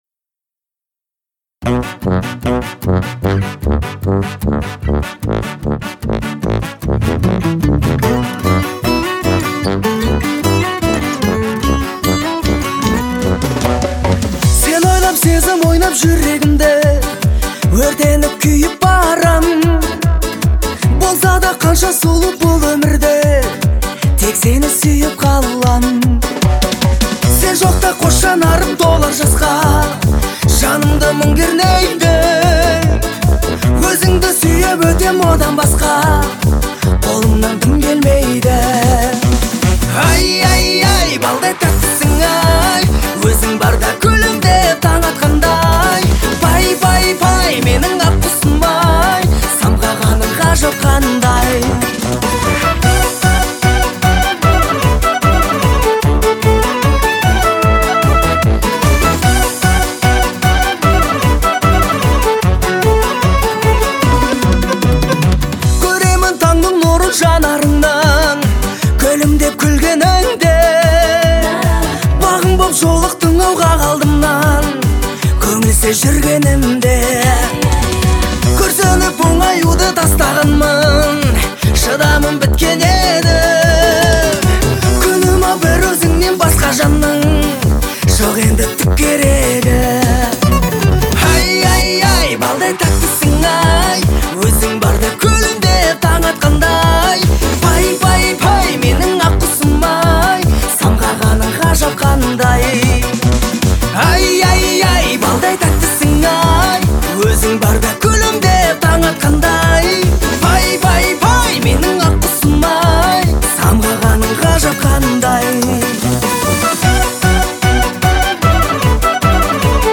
это яркий пример казахского поп-фольклора